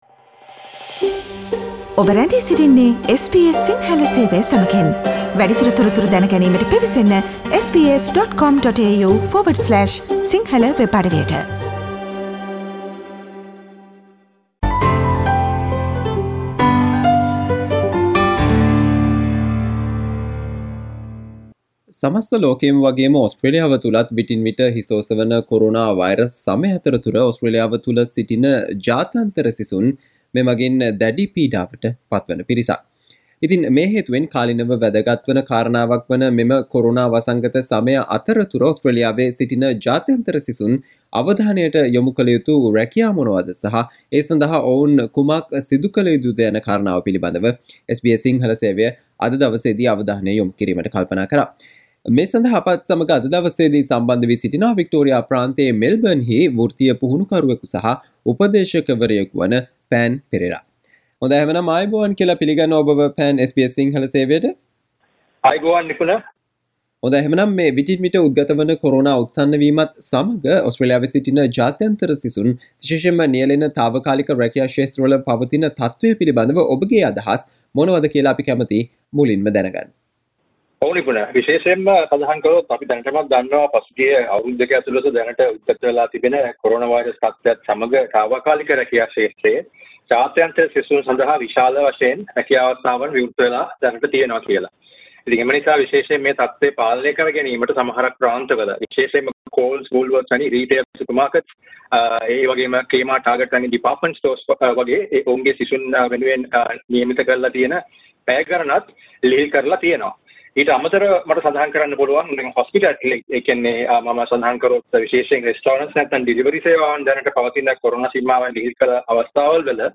මෙම කොරෝනා වසංගත සමය අතරතුර ඕස්ට්‍රේලියාවේ සිටින ජාත්‍යන්තර සිසුන් අවධානයක් යොමු කල යුතු රැකියා සහ ඒ සම්බන්ධයෙන් ඔවුන් කුමක් සිදු කලයුතුද යන්න පිළිබඳව SBS සිංහල සේවය සිදු කල සාකච්චාවට සවන්දෙන්න